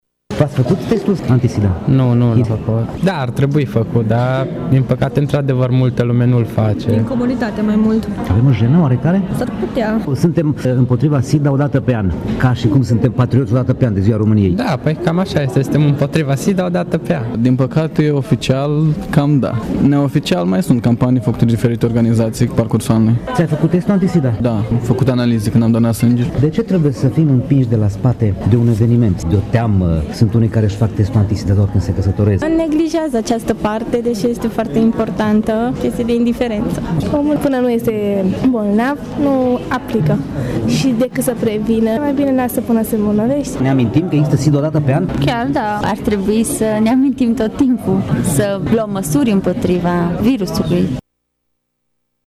Tîrgumureșenii recunosc că nu şi-au făcut testul anti SIDA, din comoditate sau din neglijenţă: